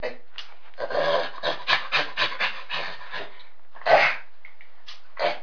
جلوه های صوتی
دانلود صدای حیوانات جنگلی 82 از ساعد نیوز با لینک مستقیم و کیفیت بالا